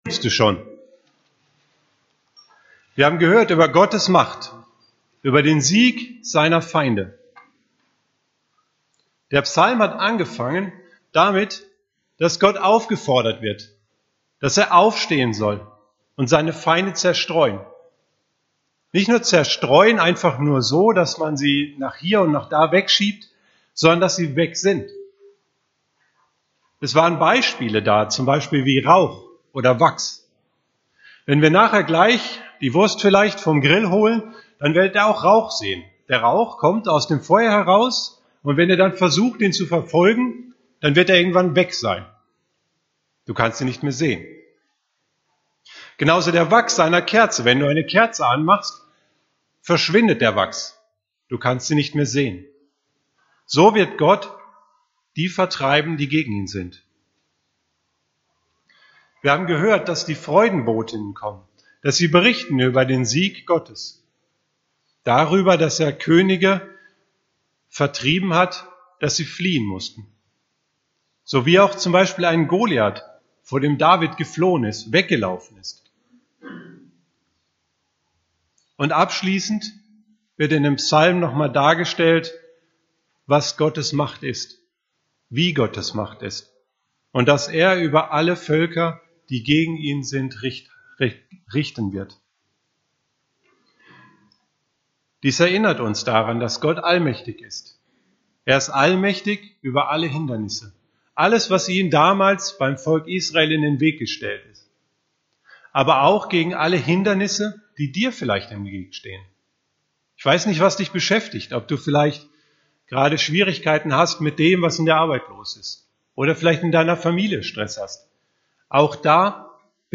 EFG Erding – Predigten